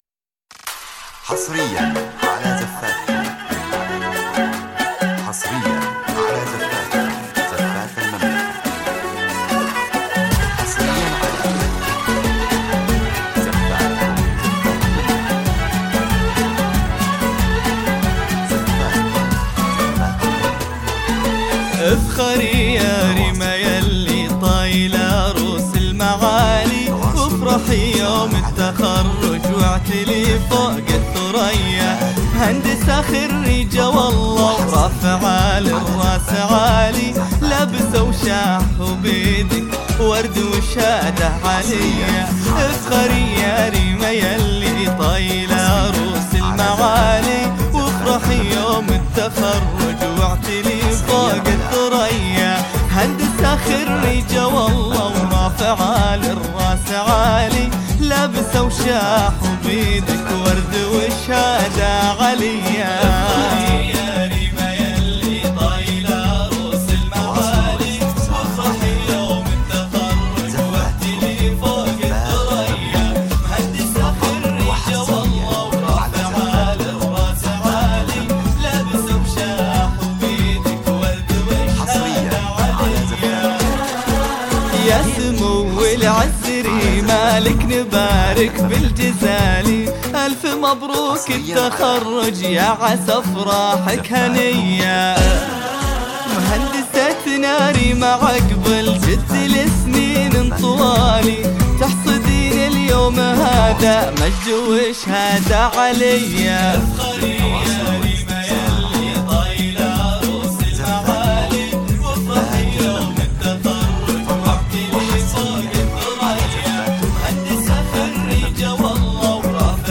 زفة تخرج فريدة تعبّر عن الفخر والعز.